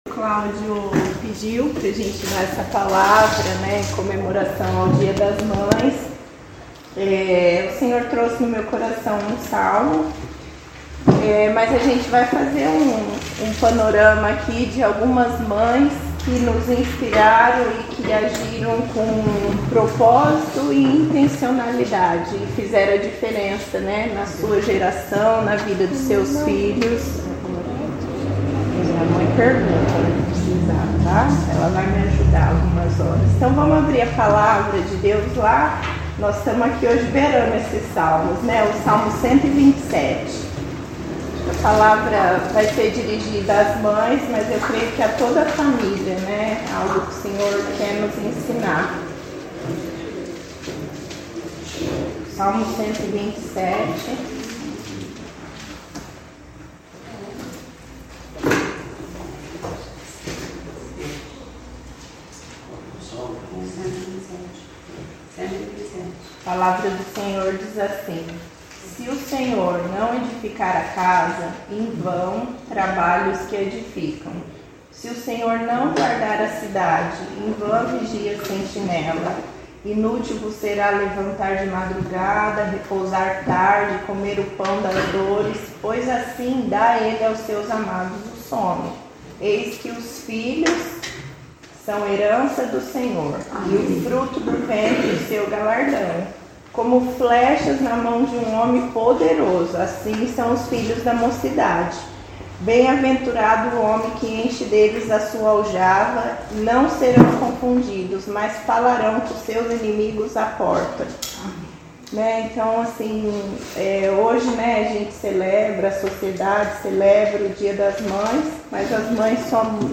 Palavras ministradas